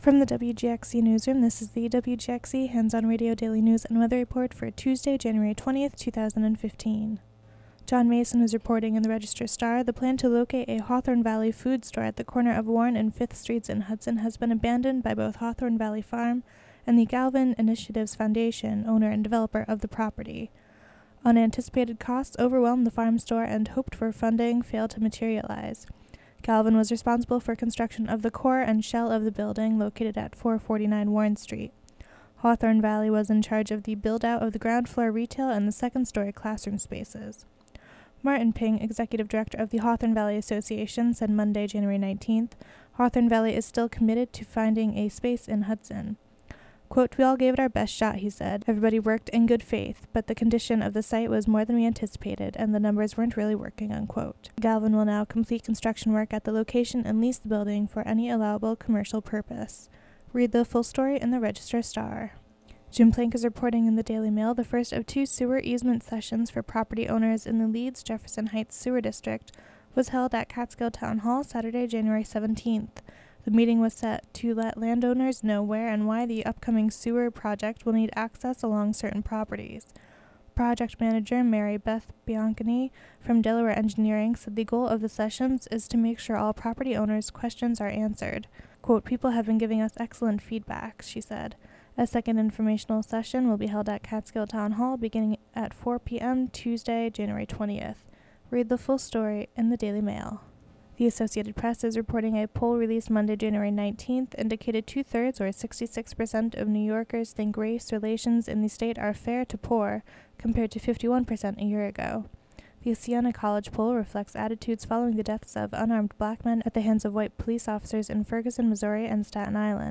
Local news and weather for Tuesday, January 20, 2015.